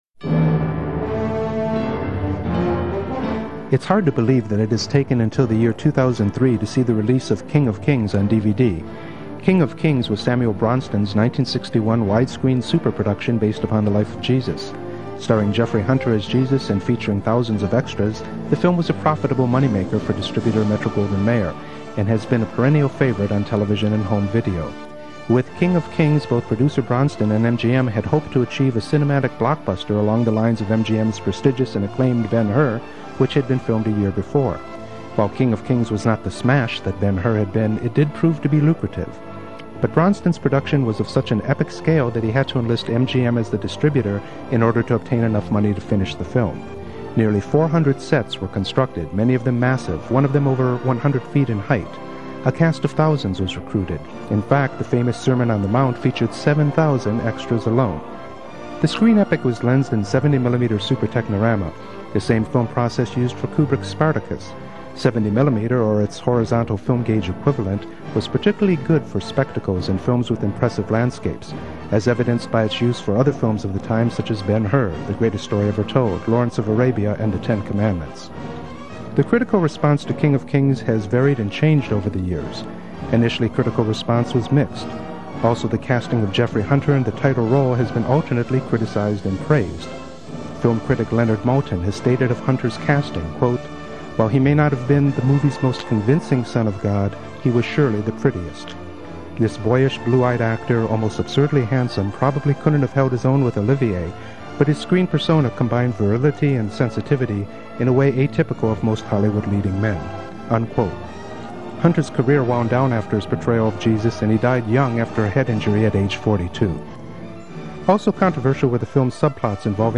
Review - King of Kings (1961) (DVD-2003)